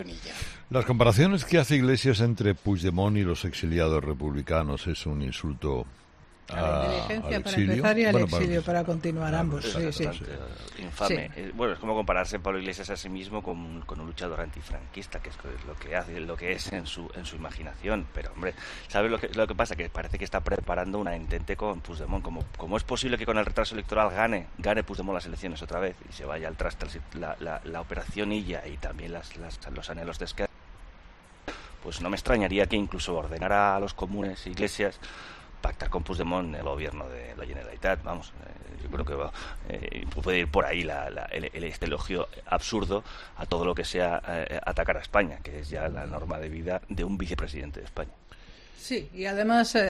El colaborador de 'Herrera en COPE', Jorge Bustos, ha analizado la última polémica que ha protagonizado el vicepresidente segundo del Gobierno